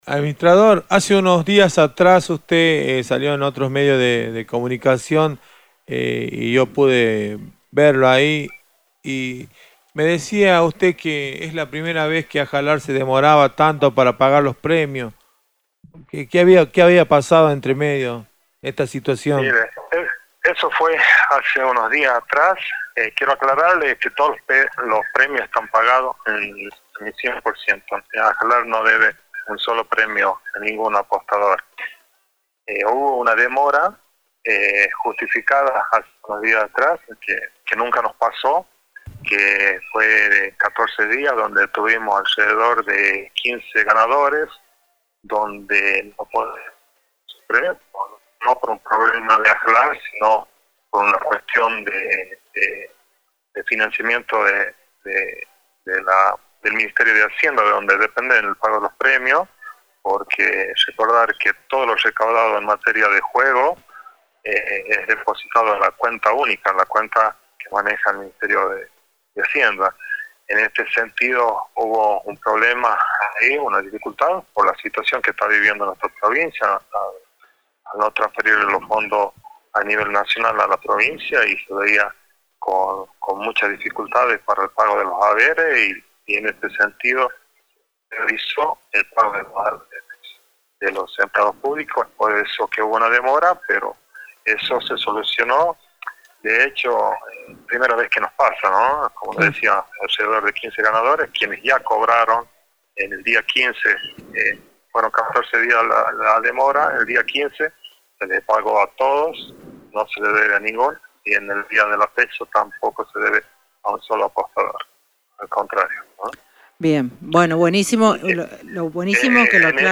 En base a las declaraciones de Ramón Vera, administrador de AJALAR (Administración de Juegos de Azar de La Rioja), en el programa Nueva Época de Radio Libertad, respecto a la demora en el pago de premios, reconoció que existen dificultades en la cadena de pagos, atribuyéndolas principalmente a dos factores: problemas de liquidez Transitorio y procesos administrativos